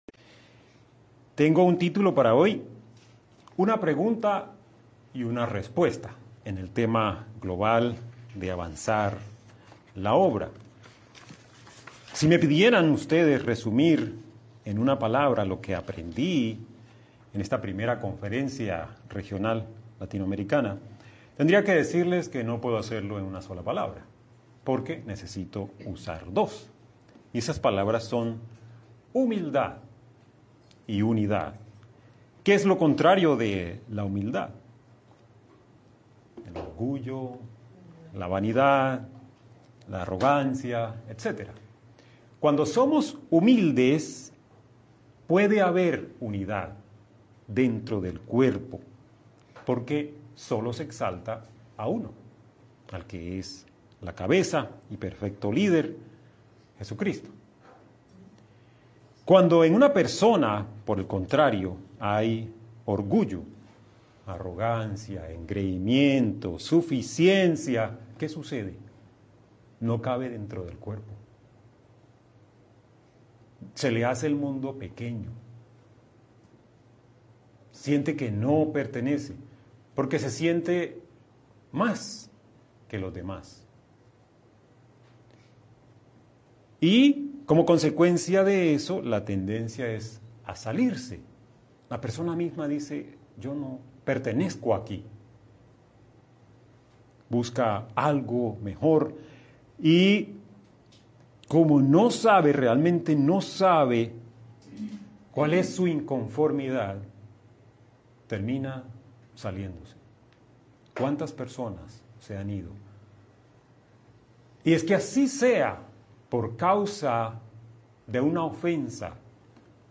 Given in Medellín